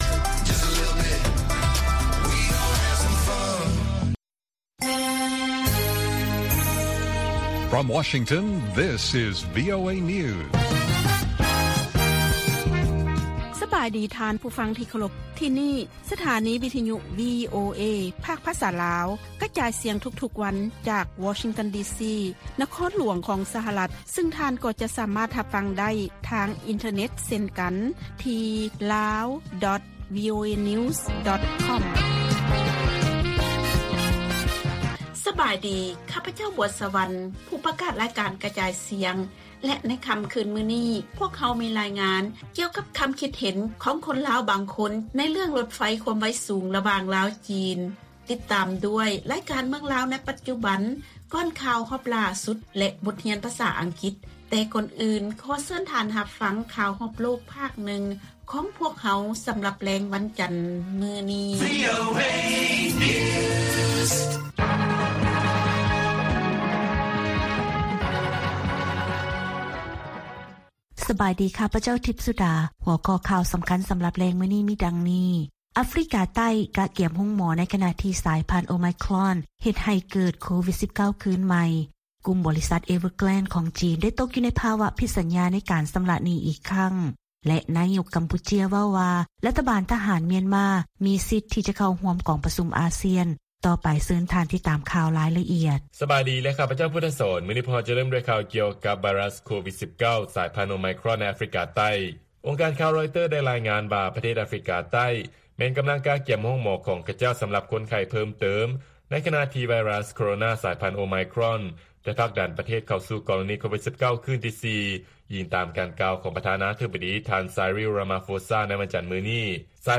ລາຍການກະຈາຍສຽງຂອງວີໂອເອ ລາວ: ອາຟຣິກາໃຕ້ ກະກຽມໂຮງໝໍ ໃນຂະນະທີ່ສາຍພັນ ໂອໄມຄຣອນ ເຮັດໃຫ້ເກີດ COVID-19 ຄື້ນໃໝ່